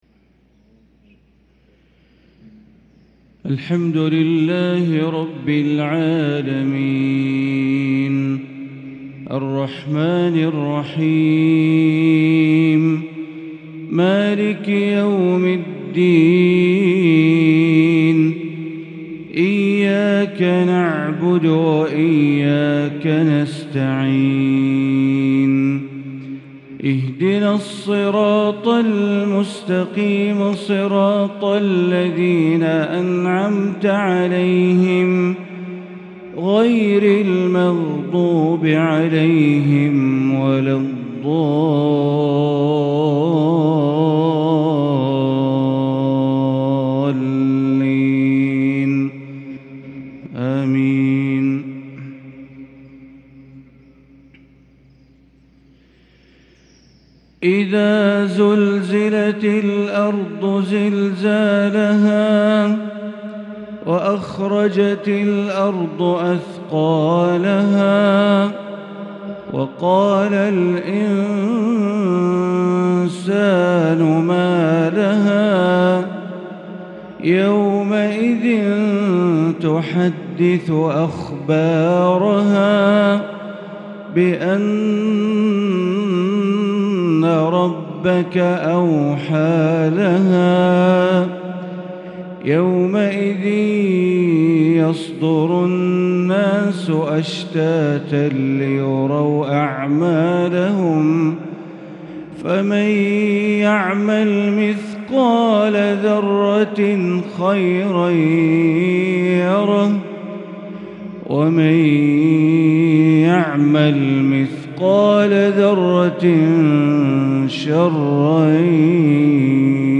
Maghrib prayer Surat Az-Zalzala & Al-Aadiyat 5-5-2022 > 1443 > Prayers - Bandar Baleela Recitations